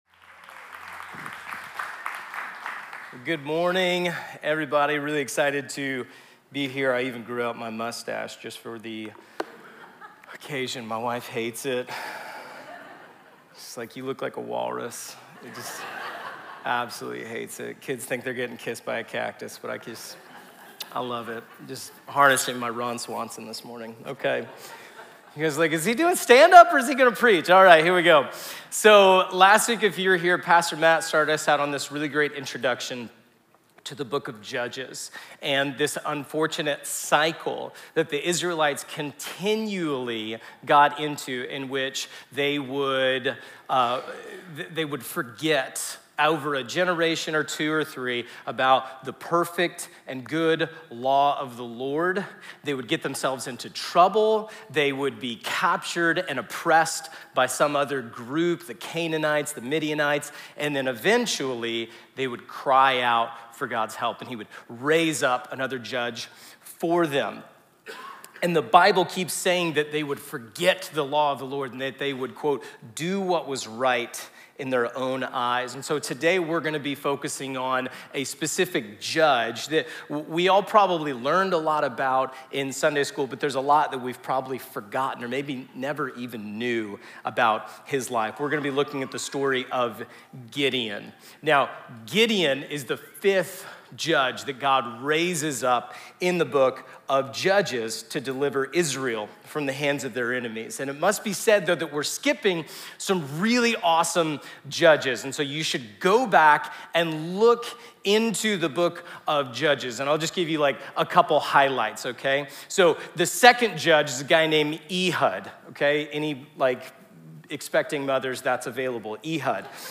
Weekly messages from City Church Tulsa